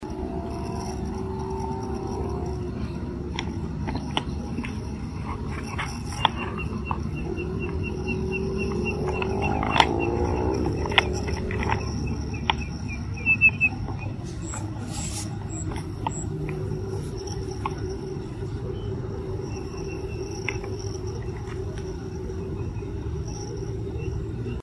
Spotted Nothura (Nothura maculosa)
Molestan algunos ruidos de motocicletas que estaba en la pista que no queda muy lejos, pero se aprecian claramente los cantos..
Location or protected area: Reserva Natural Urbana La Malvina
Condition: Wild
Certainty: Recorded vocal